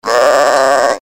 zvuk-barana_006
zvuk-barana_006.mp3